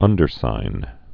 (ŭndər-sīn)